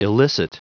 Prononciation du mot elicit en anglais (fichier audio)
Prononciation du mot : elicit